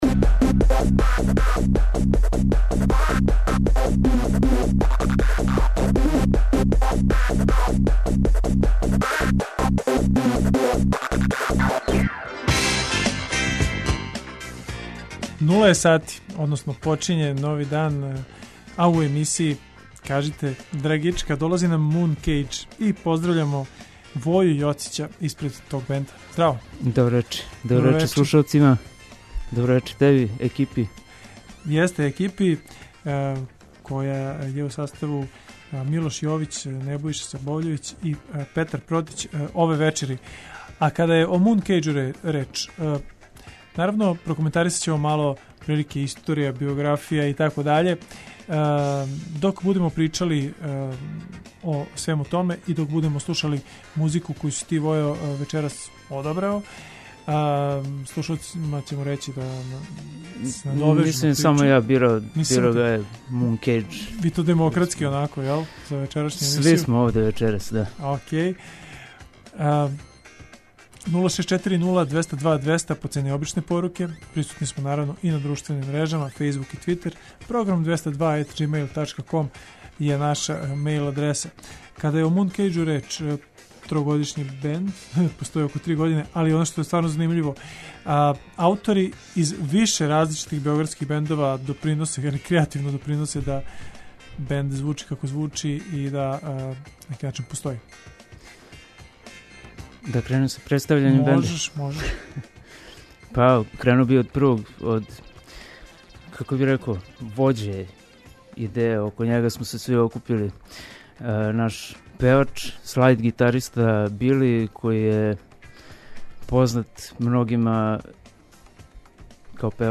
Mooncage ће на нашој станици испунити сат времена емисије пробраном музиком док ће чланови, свако из свог угла, говорити о искуствима са сцене, новим музичким идејама и плановима за наступе.